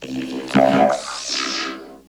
68.6 VOCOD.wav